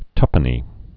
(tŭpnē)